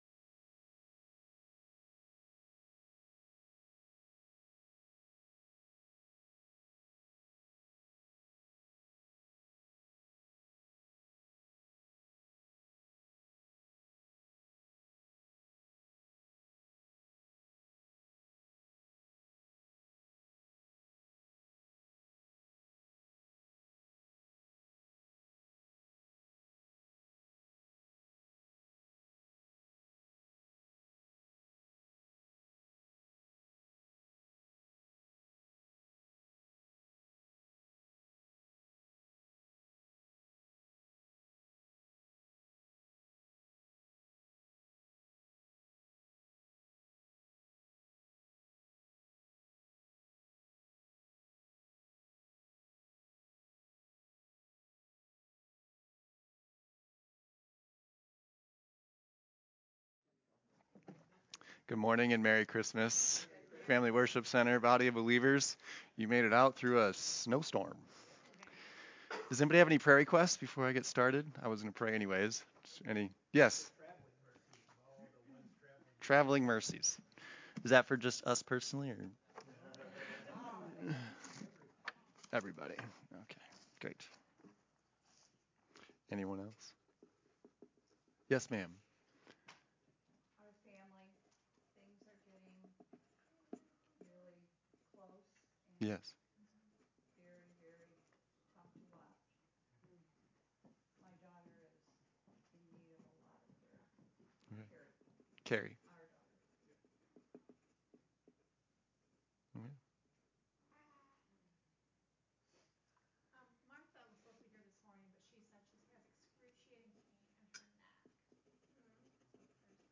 Sermon
What-did-the-Angels-say-Sermon-Audio-CD.mp3